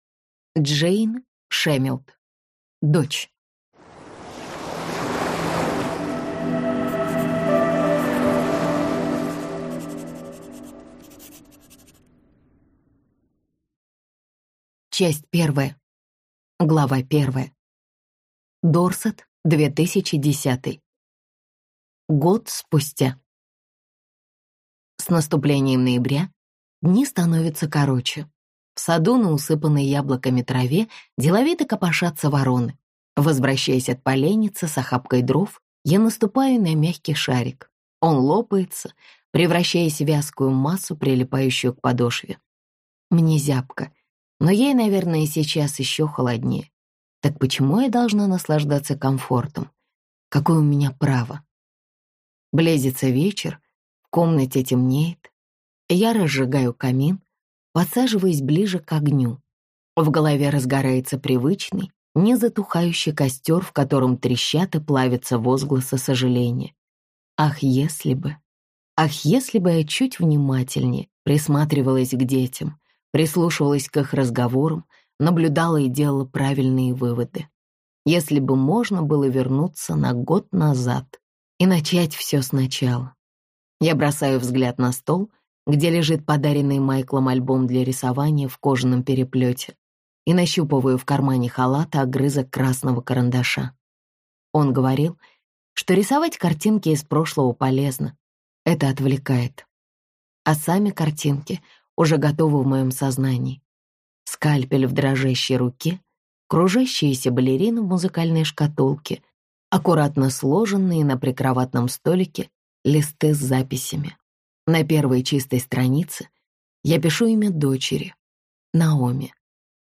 Аудиокнига Дочь | Библиотека аудиокниг